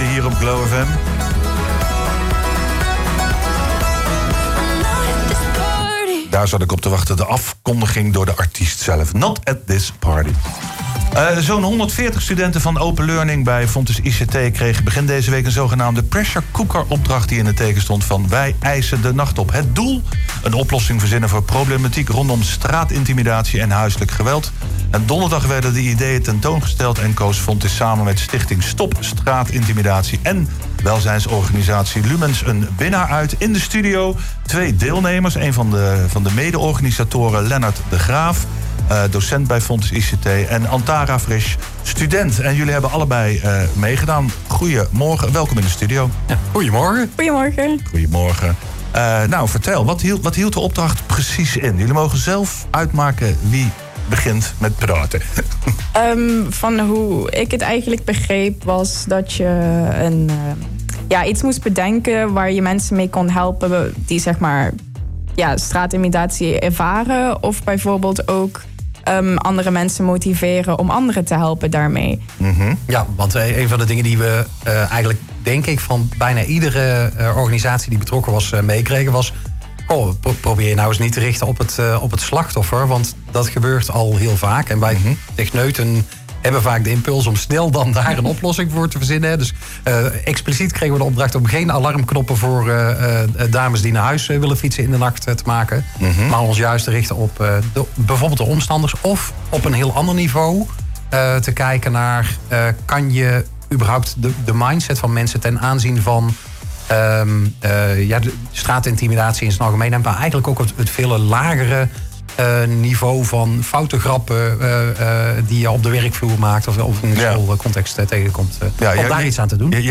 were guests on Radio Glow FM to discuss the ‘We Demand the Night’ hackathon.